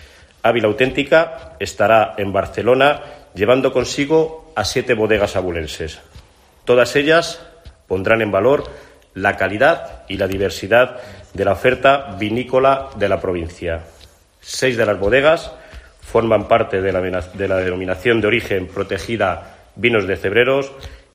El diputado de Desarrollo Rural y Medio Ambiente, Jesús Martín, ha explicado que irán a Barcelona con 7 bodegas abulenses.